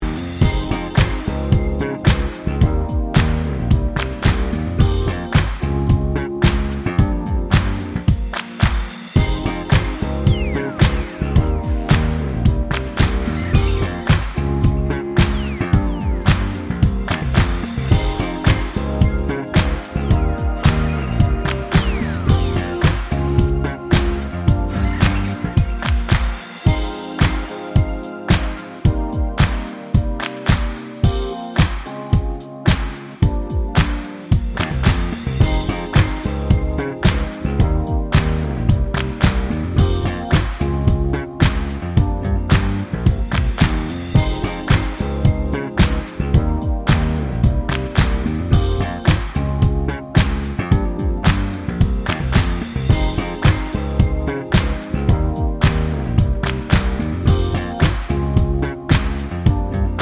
no Backing Vocals R'n'B / Hip Hop 3:40 Buy £1.50